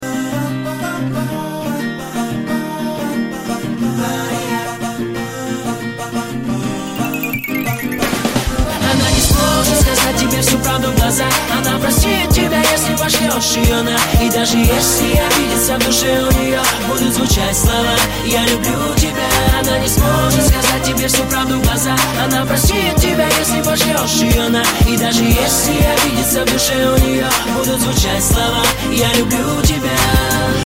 мужской вокал
Хип-хоп
дуэт
RnB